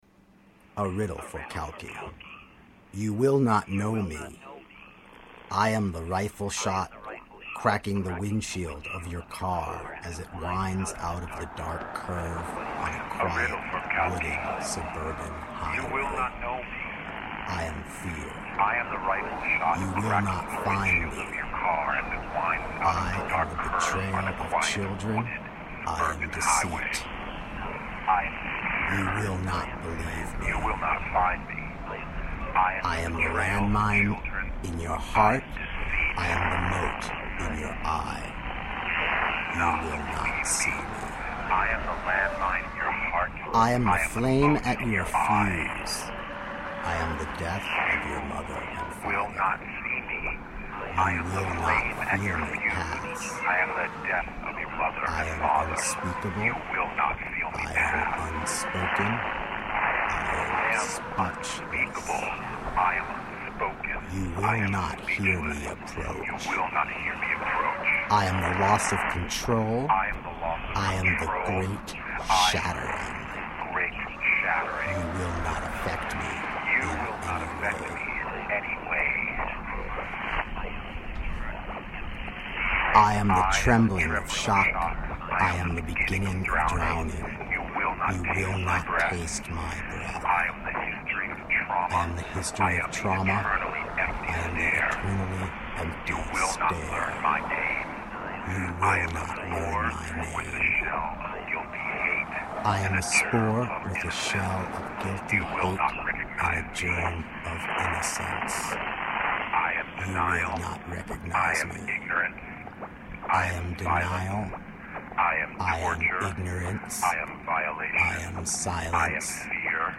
We recorded these poems shortly after the events discribed.